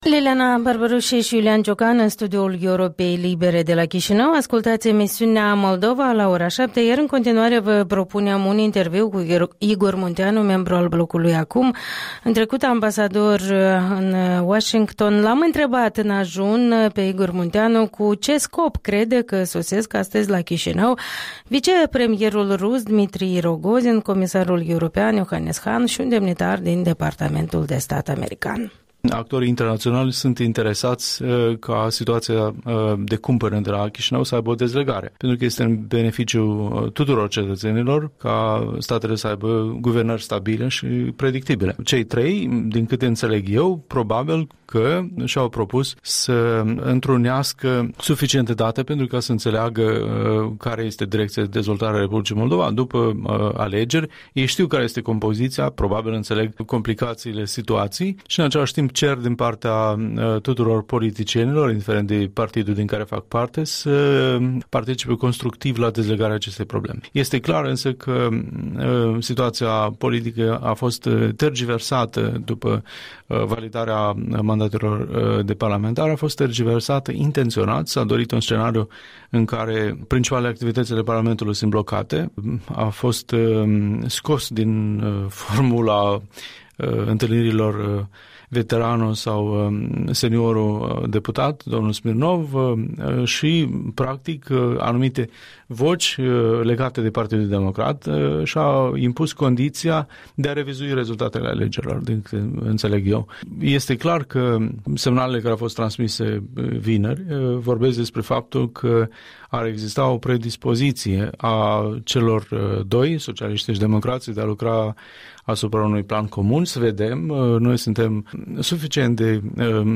Interviul dimineții cu Igor Munteanu, deputat al Blocului ACUM și fost ambasador al R. Moldova în Statele Unite.